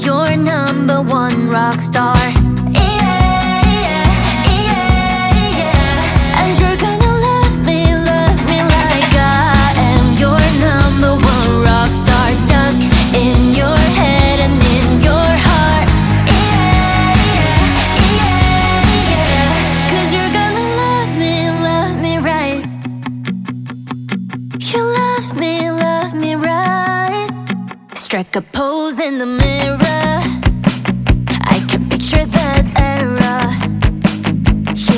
Жанр: Поп музыка
K-Pop, Pop